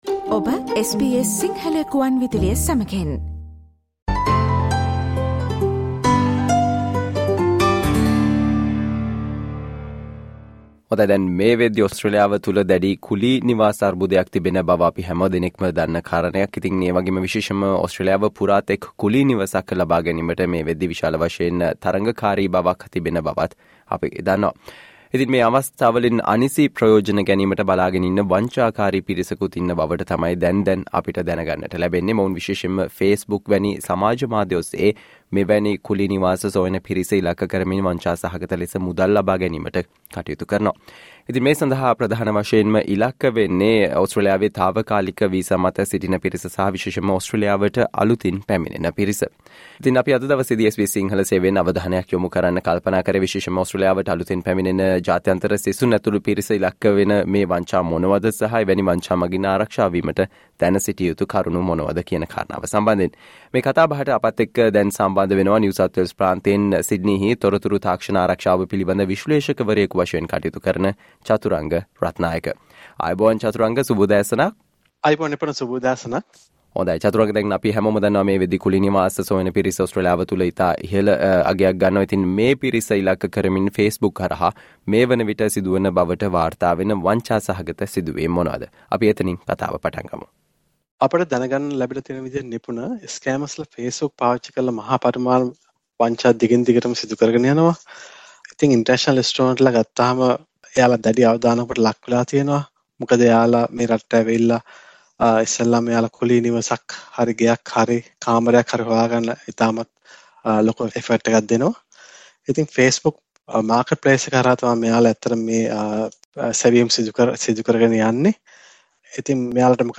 Listen to the SBS Sinhala discussion on What international students should know to protect themselves from Facebook scams in Australia